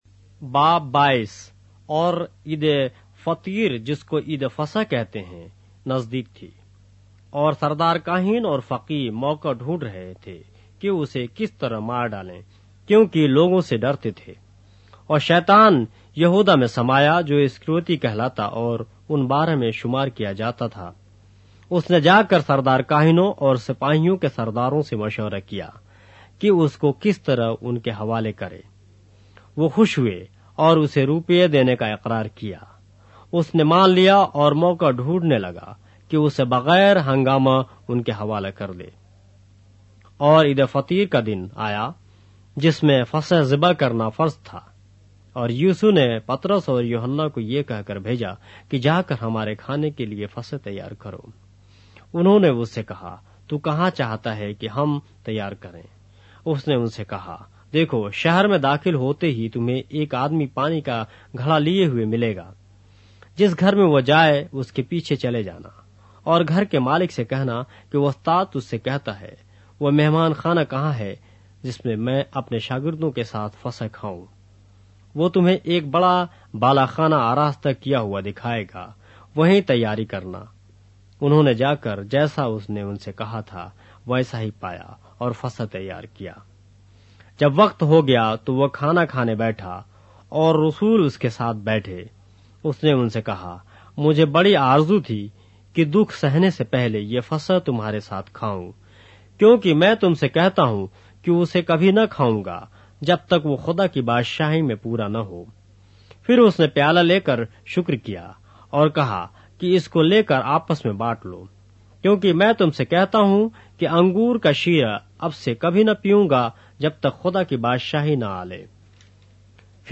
اردو بائبل کے باب - آڈیو روایت کے ساتھ - Luke, chapter 22 of the Holy Bible in Urdu